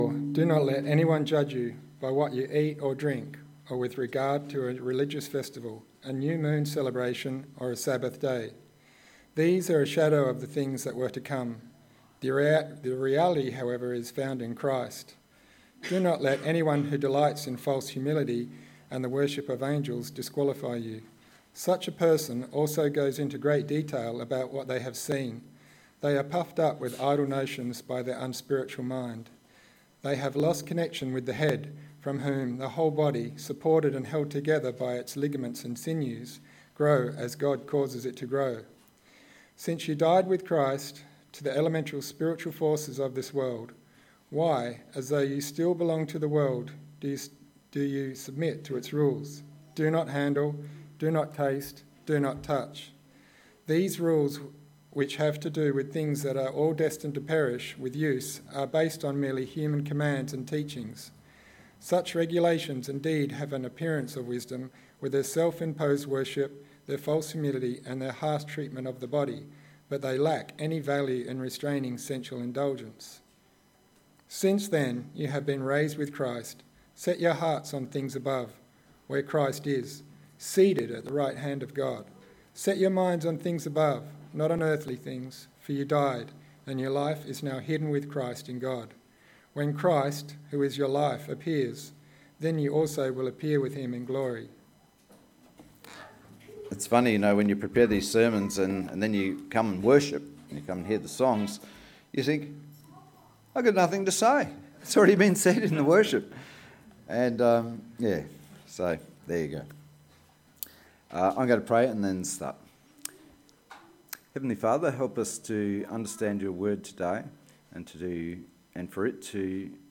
This sermon inspires us to ‘Set Our Minds on Things Above’. When we get too concerned about our owns success and perfection, we lose sight of God.
Service Type: Sunday AM